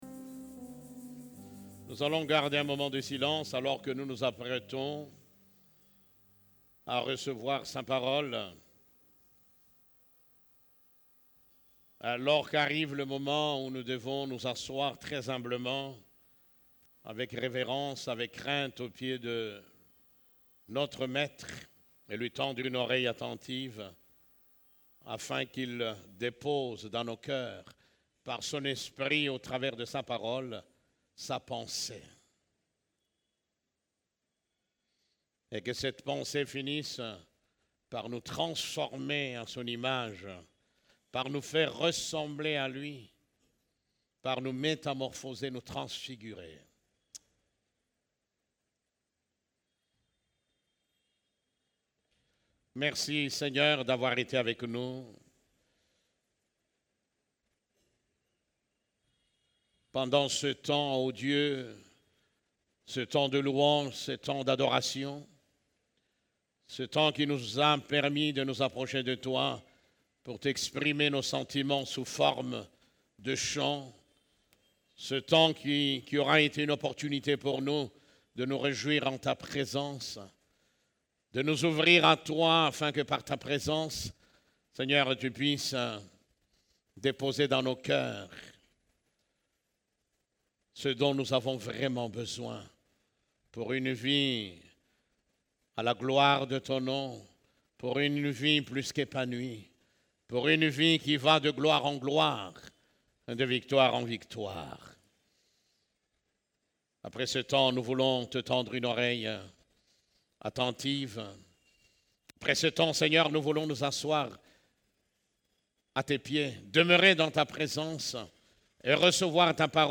CEF la Borne, Culte du Dimanche, Le lieu secret où il fait bon d'être